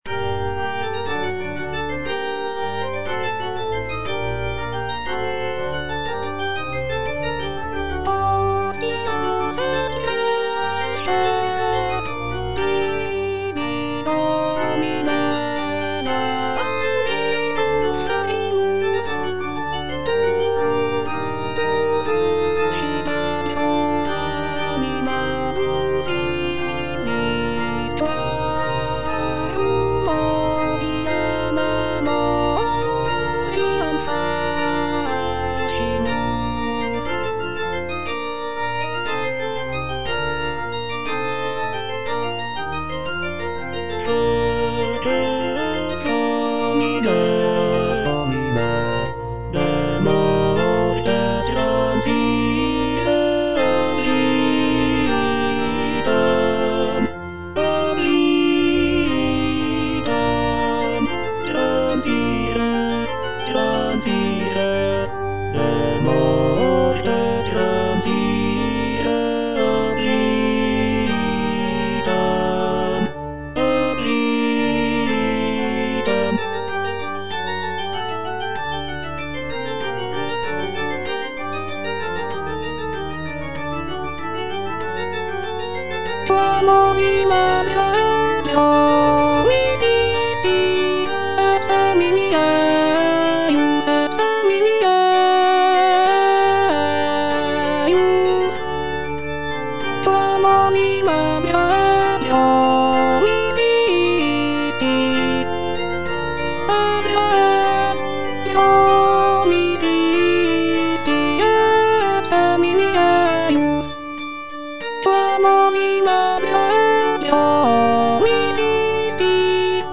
M. HAYDN - REQUIEM IN C (MISSA PRO DEFUNCTO ARCHIEPISCOPO SIGISMUNDO) MH155 Hostias et preces - Alto (Voice with metronome) Ads stop: auto-stop
It was written as a requiem mass in memory of Archbishop Sigismund von Schrattenbach. The work is characterized by its somber and mournful tone, reflecting the solemnity of a funeral mass.